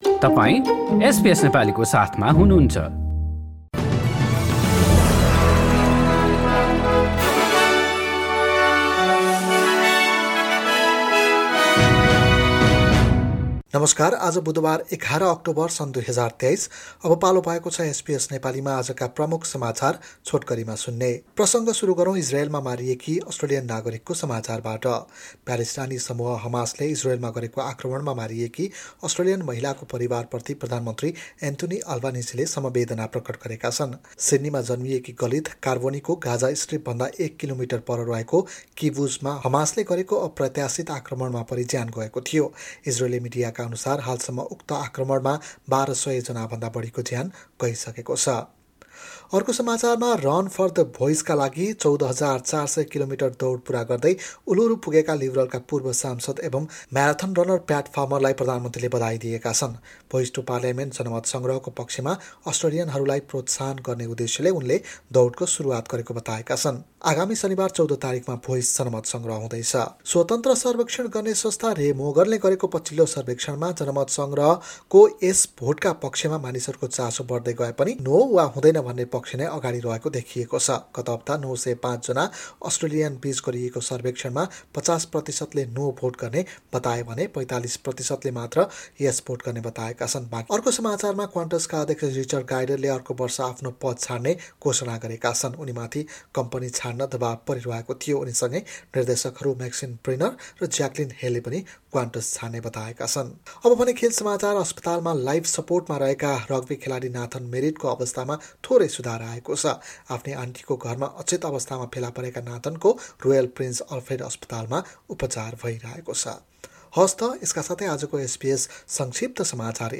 SBS Nepali Australian News Headlines: Wednesday, 11 October 2023